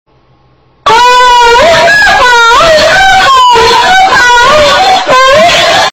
Play Cepo De Madera Explodindo - SoundBoardGuy
Play, download and share Cepo de madera explodindo original sound button!!!!